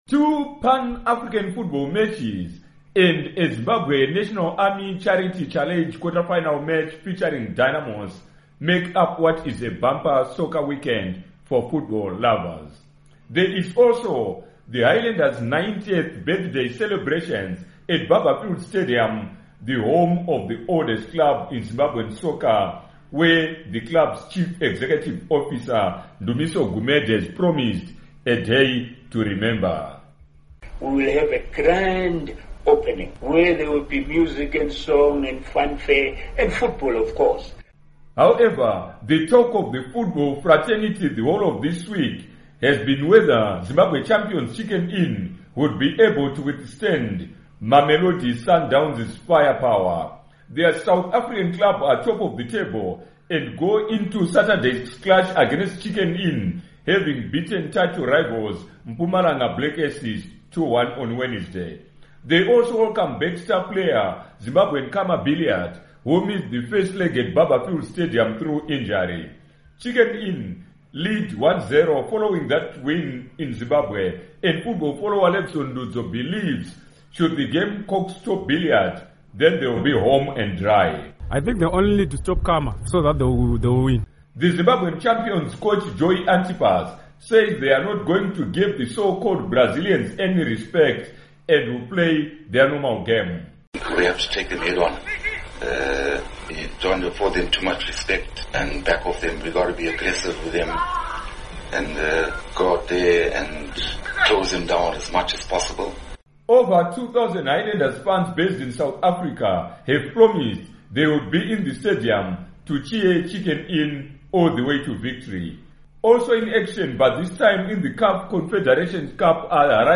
Report on Weekend Soccer Action